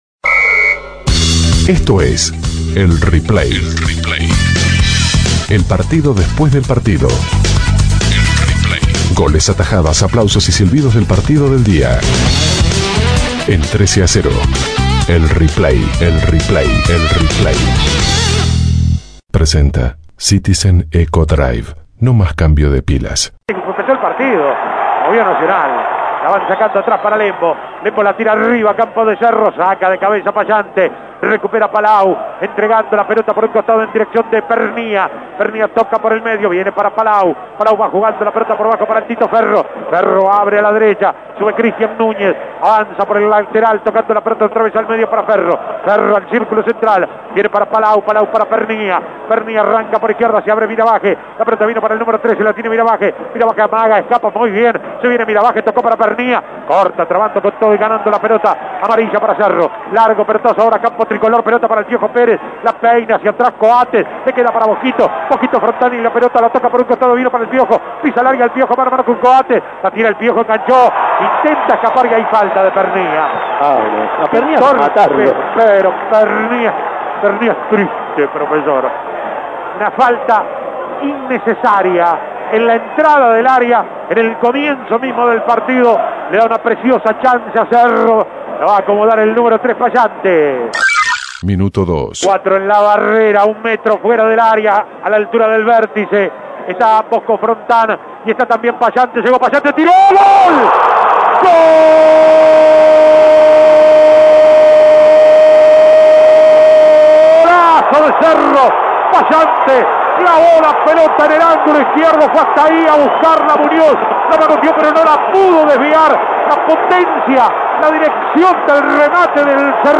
Escuche los goles y las jugadas destacadas con el relato
Goles y comentarios Escuche el replay de Cerro - Nacional Imprimir A- A A+ Cerro goleó 4 a 1 a Nacional por la séptima fecha del torneo Apertura 2010.